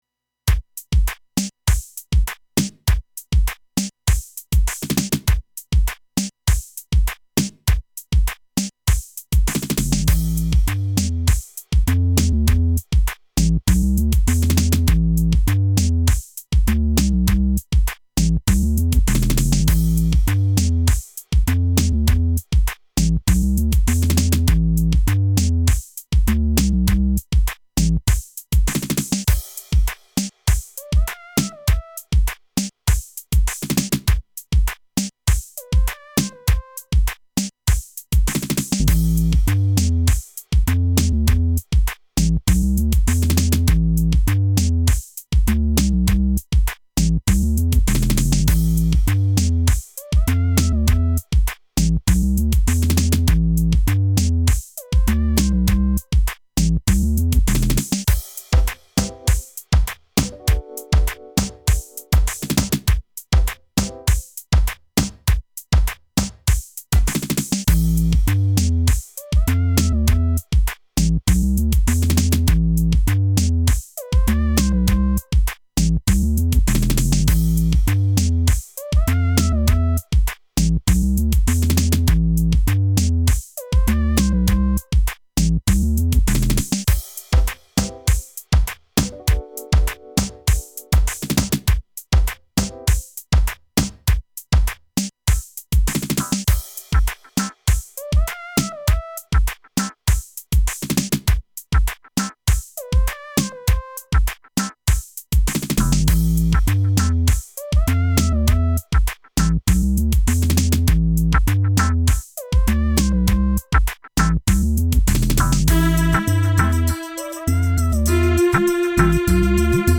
drums, synthesizers, child's toy, and string quartet